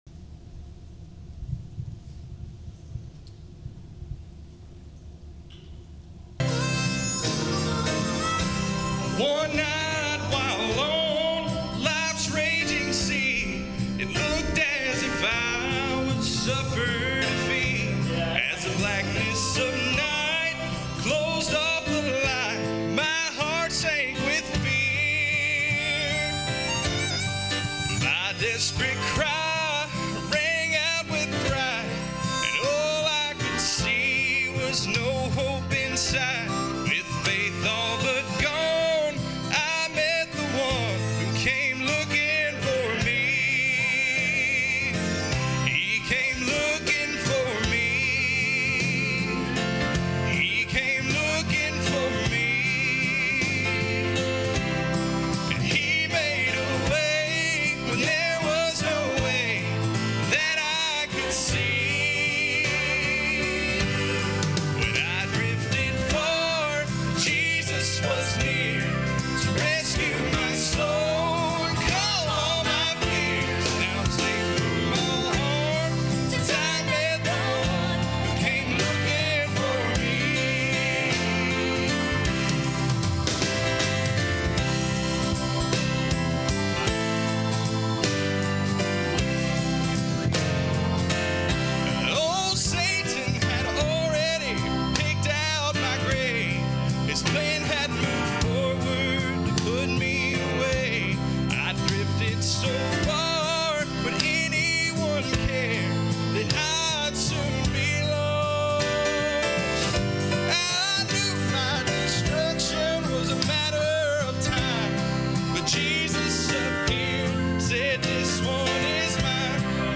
Sermons | Richardson's Cove Baptist Church